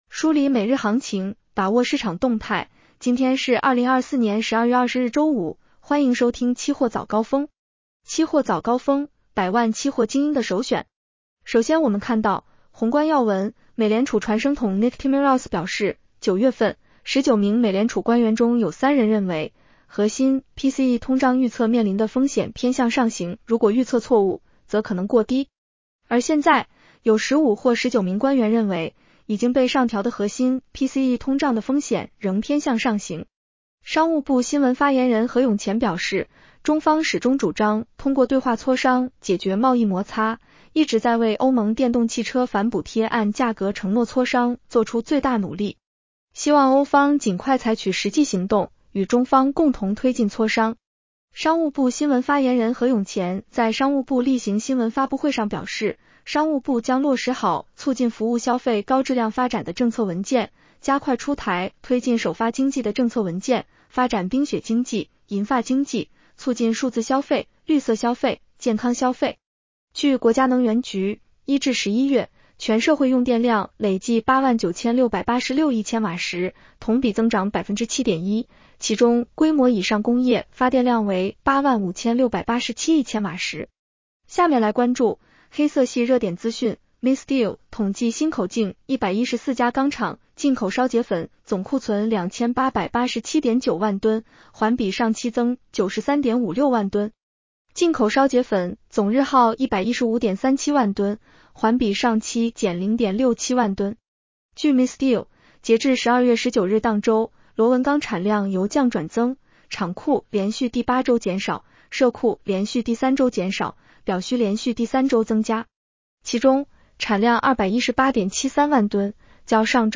期货早高峰-音频版